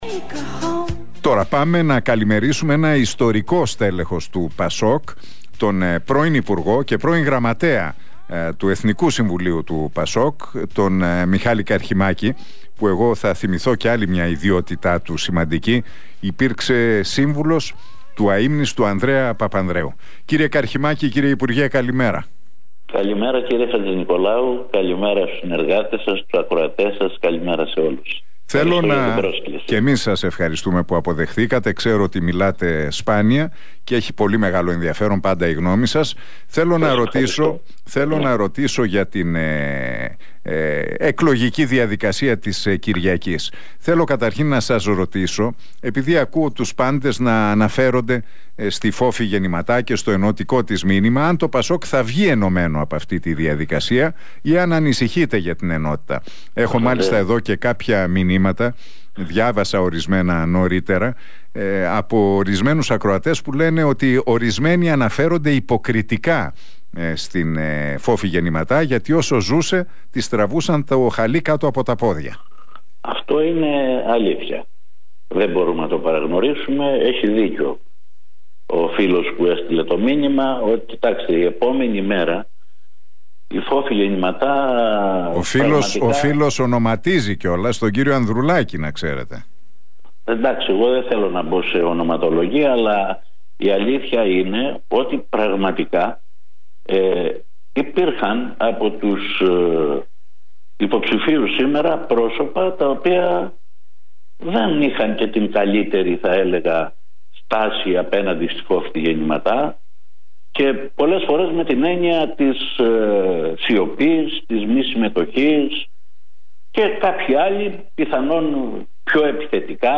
Ο Μιχάλης Καρχιμάκης σχολίασε τις επερχόμενες εκλογές του ΚΙΝΑΛ, μιλώντας στον Realfm 97,8 και στην εκπομπή του Νίκου Χατζηνικολάου.